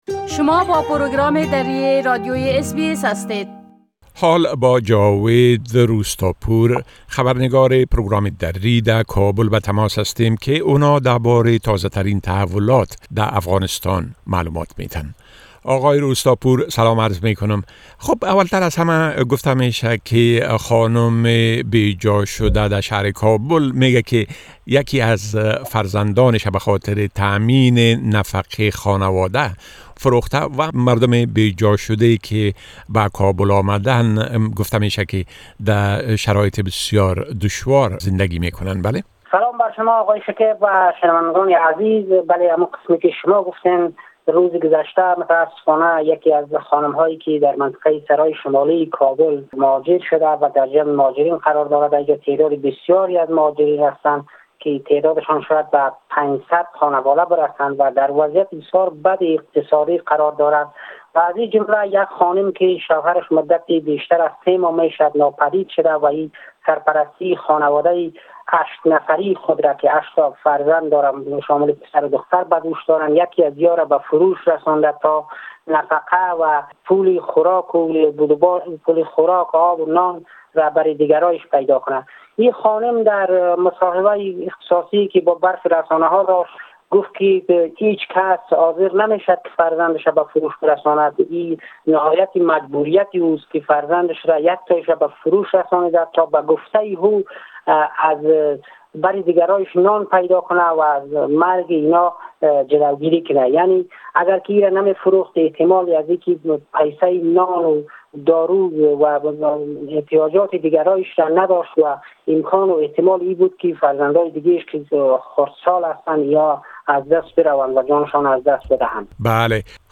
گزارش كامل خبرنگار ما در كابل، به شمول اوضاع امنيتى و تحولات مهم ديگر در افغانستان، را در اينجا شنيده ميتوانيد.